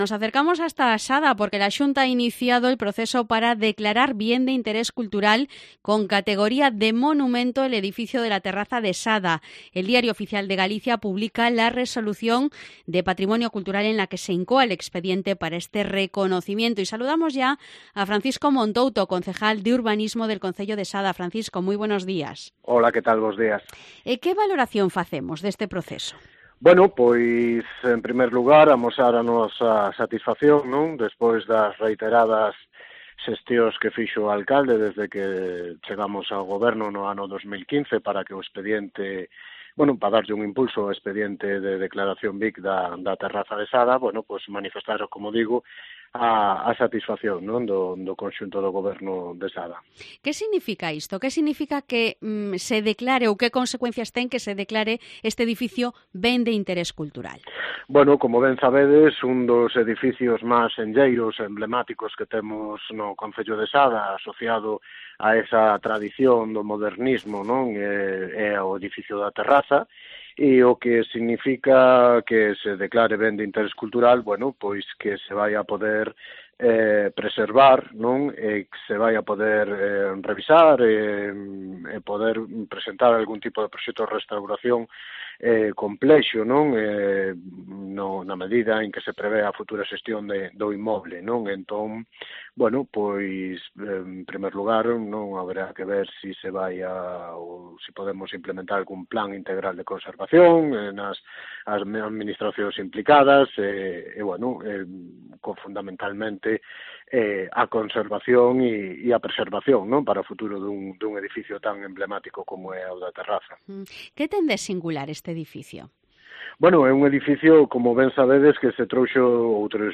Entrevista a Francisco Montouto, concejal de Urbanismo de Sada